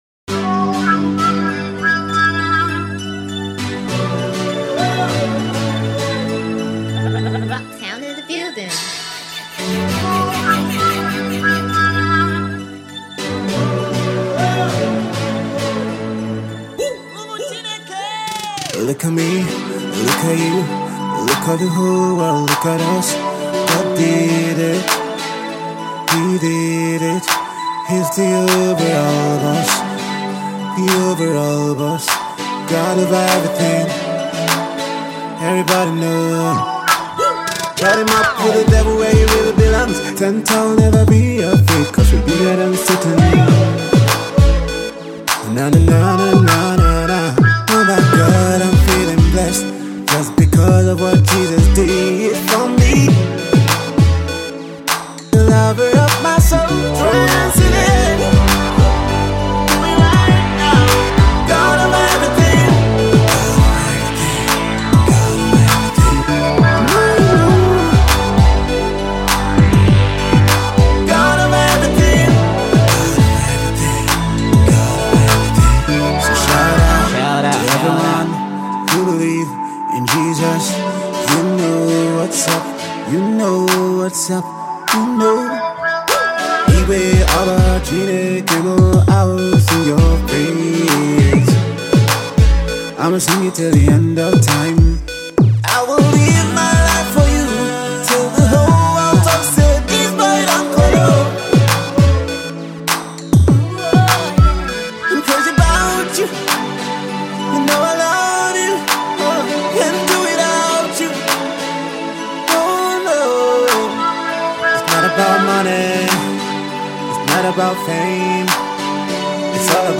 fusing electronic elements with spirited gospel melodies